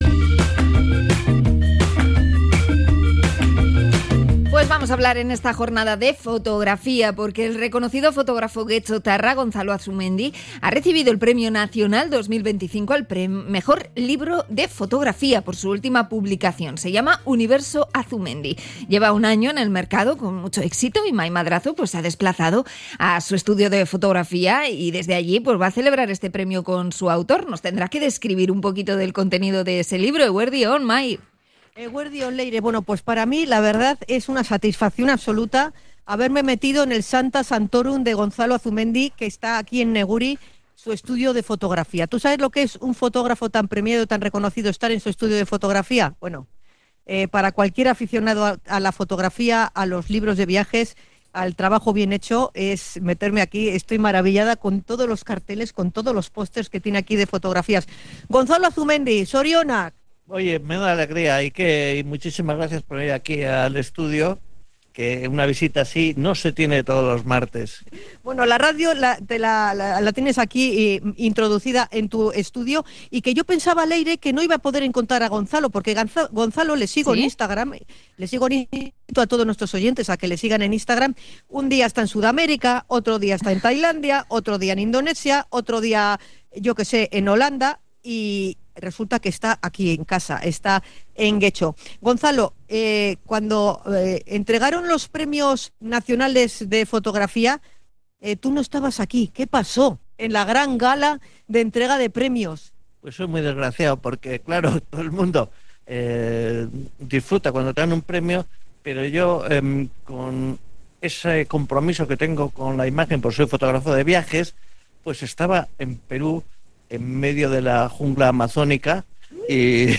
Hemos estado en el estudio del fotógrafo para celebrar el premio